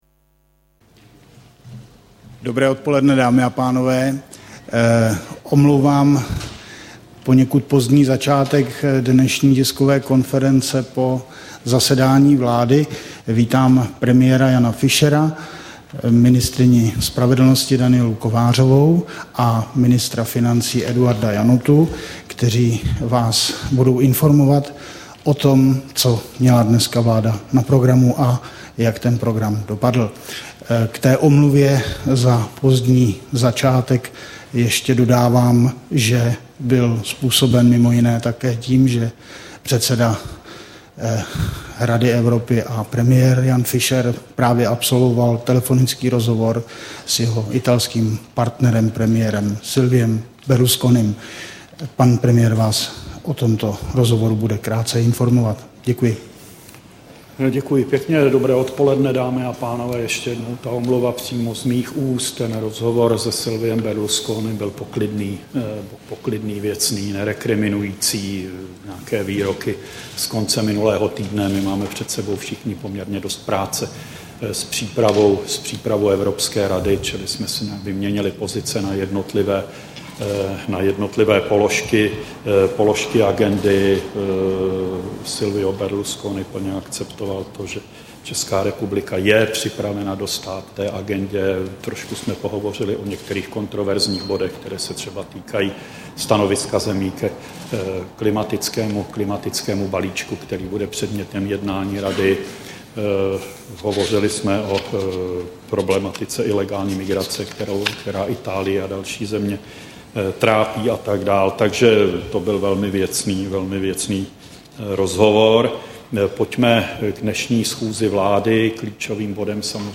Tisková konference po zasedání vlády, 1. června 2009